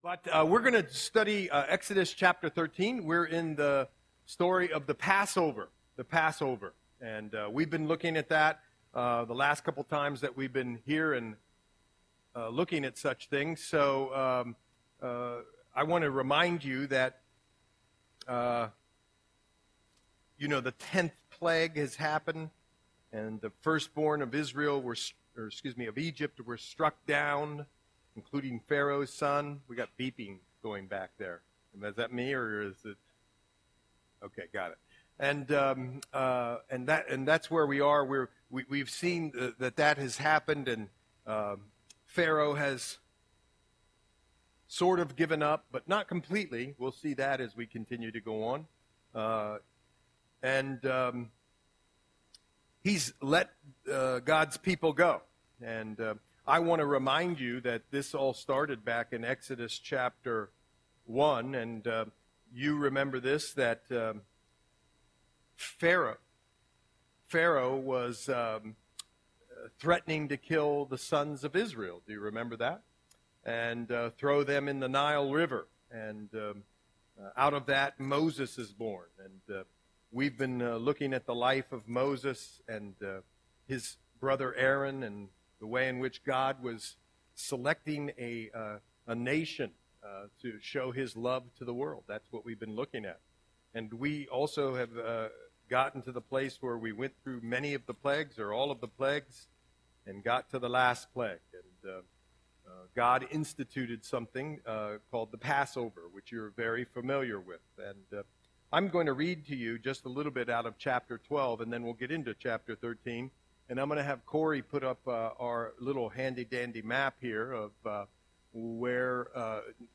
Audio Sermon - January 8, 2025